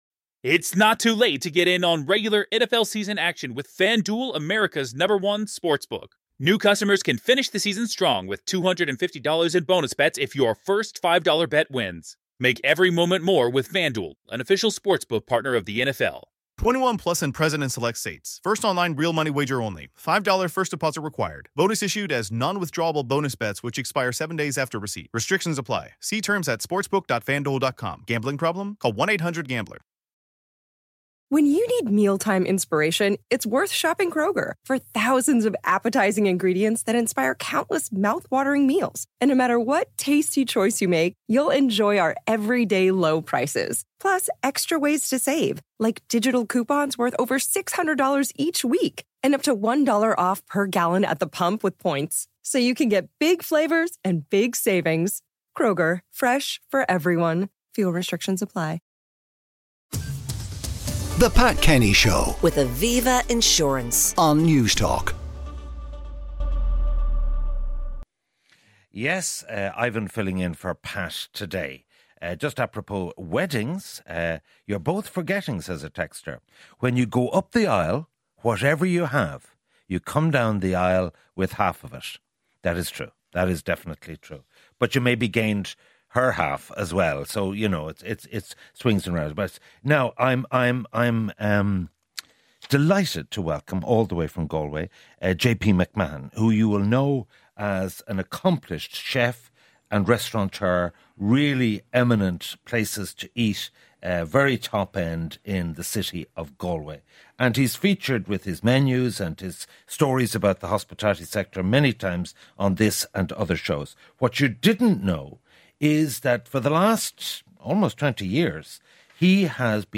Pat brings you the sharpest analysis of news and current affairs on the radio and fresh perspectives on the issues that will define a generation. Breaking news is interwoven with reflective news features and reports from a variety of reporters based across the country.